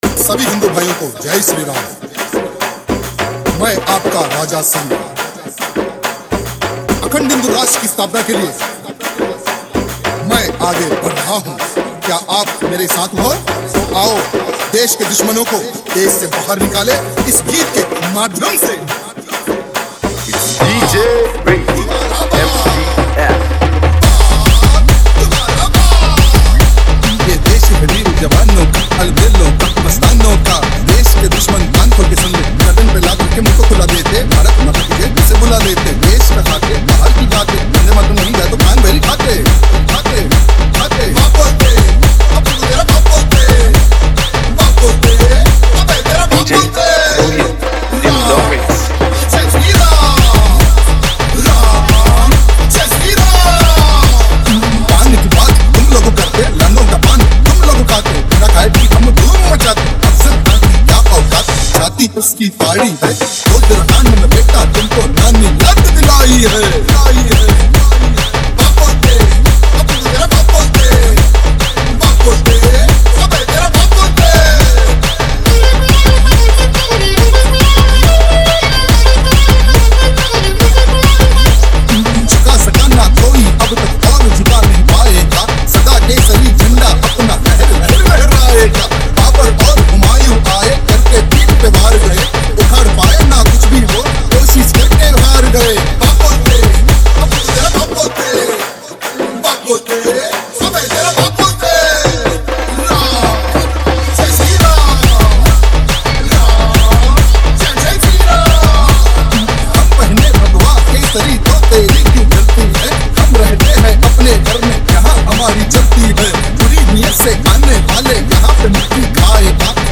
Dj Song Remix